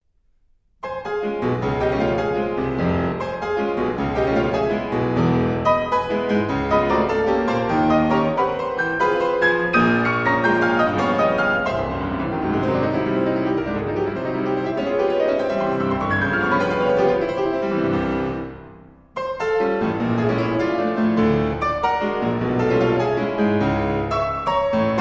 in F major: Andante con moto e grazioso